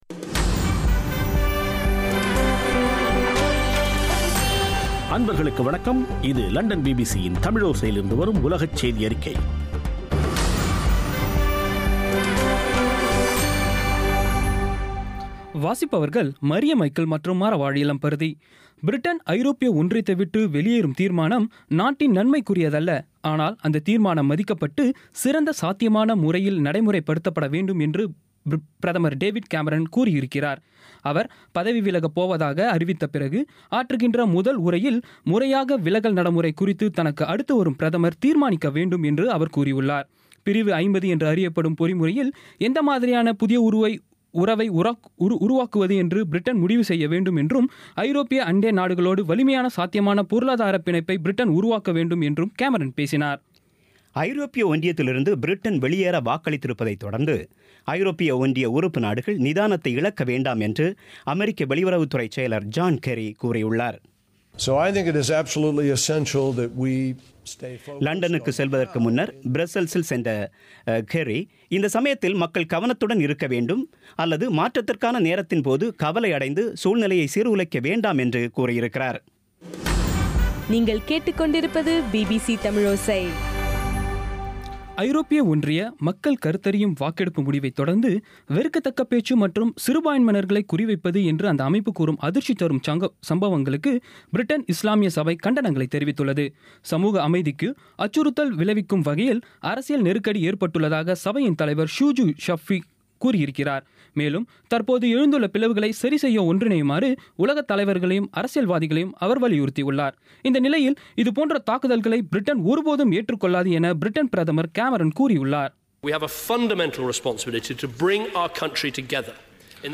இன்றைய (ஜூன் 27ம் தேதி ) பிபிசி தமிழோசை உலக செய்தியறிக்கை